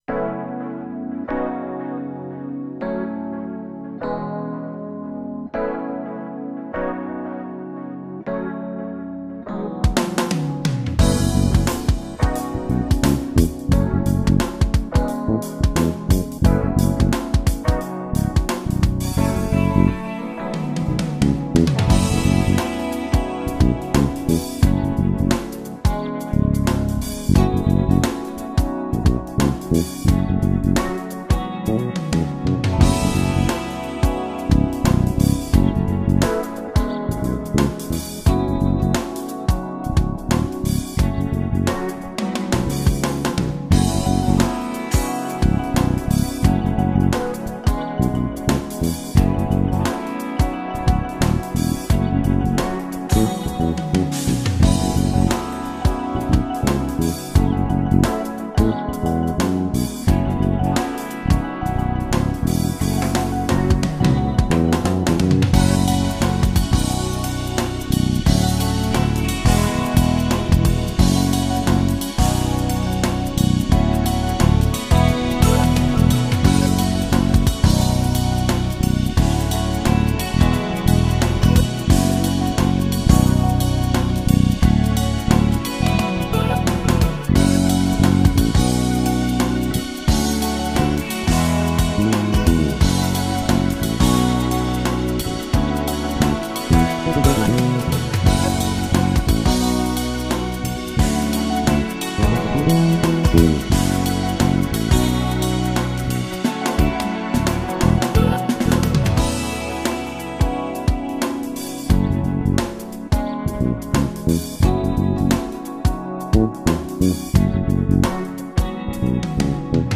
Jedná se 5ku Lakland Joe Osborne 5 - USA model, palisandrový hmatník, hráno na snímač u kobylky, protože tam je podle mne rozdíl nejvíce slyšet.
Hčko bylo trochu méně artikulované a všiml jsem si, že se mi mnohem více líbí zvuk vyšších tónů.